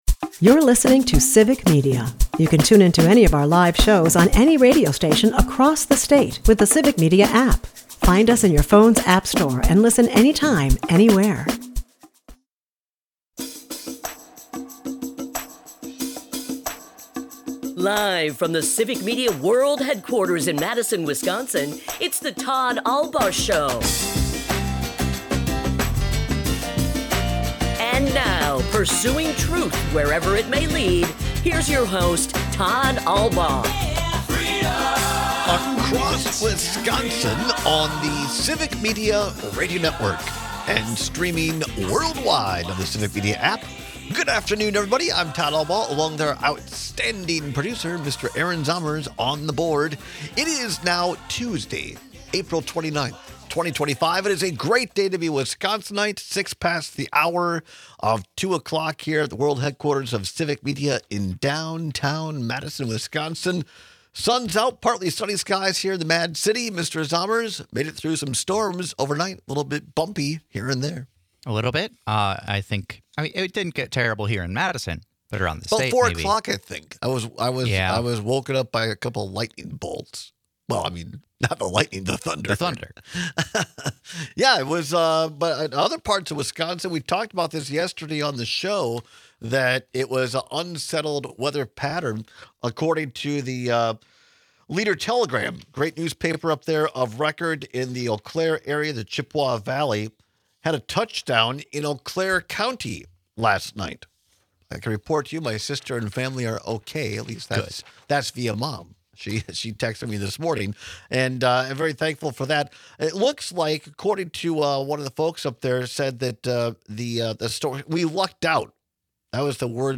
We take some calls this hour on how Trump’s first 100 days have affected Wisconsinites in their day-to-day lives.